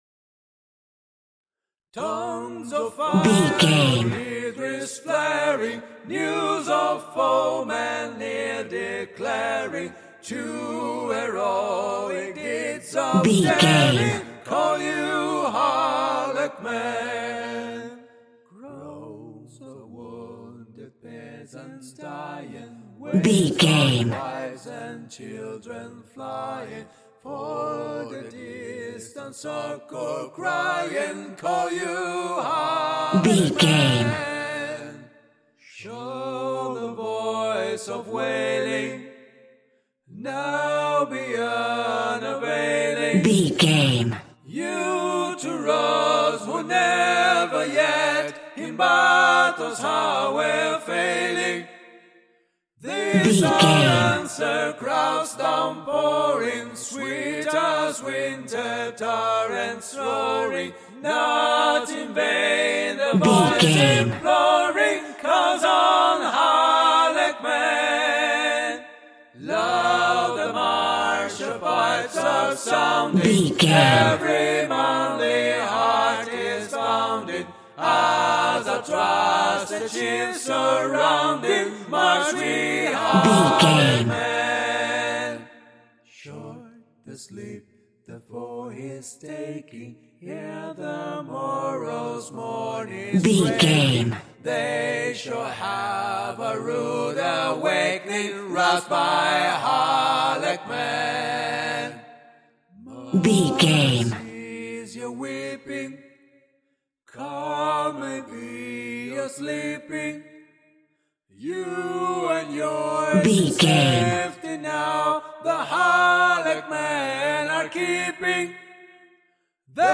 Aeolian/Minor
groovy